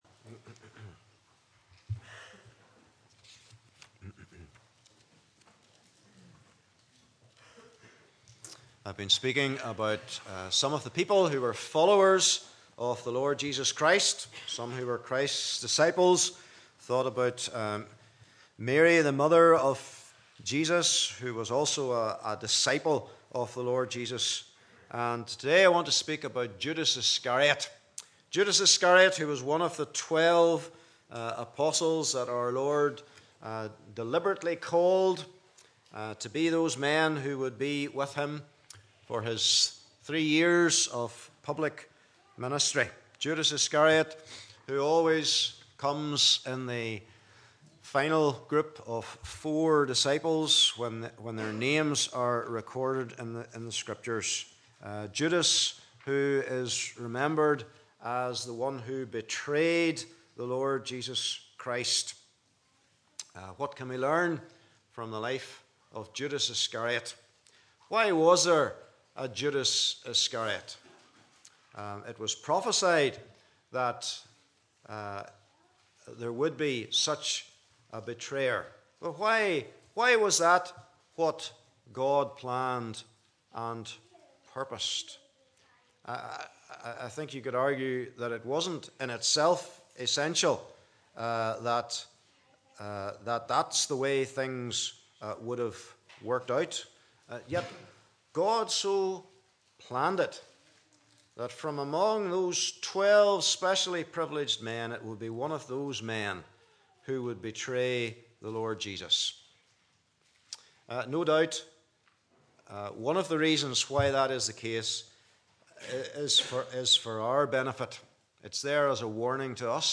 Passage: Matthew 26:6-16 Service Type: Morning Service